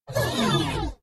大笑的哔哔声